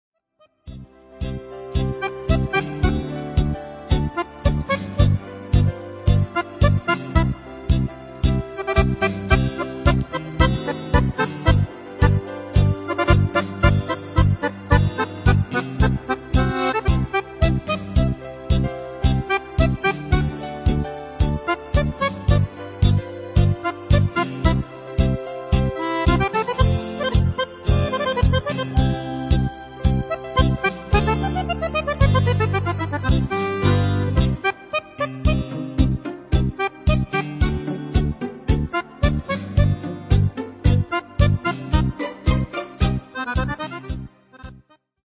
lento